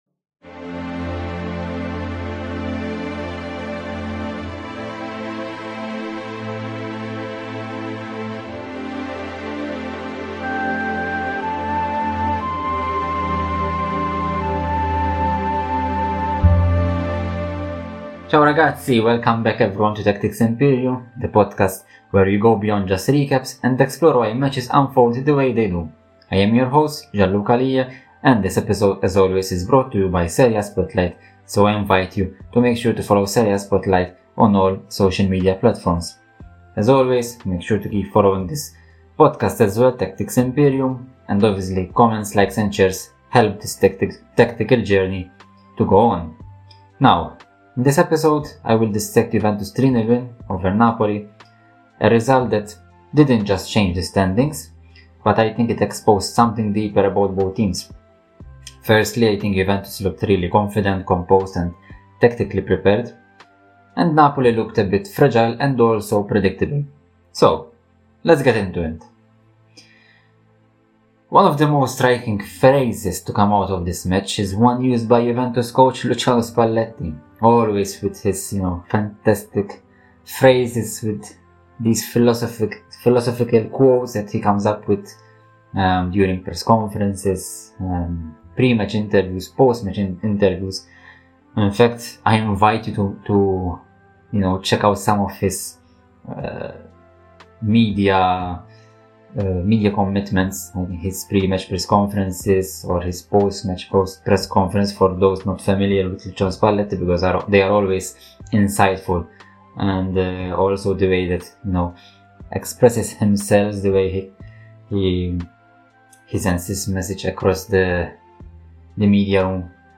We cover every game in Italy's top football league with a light-hearted twist. Stay tuned for in-depth game reviews, lively discussions, and all the laughter you need to keep up with Serie A action!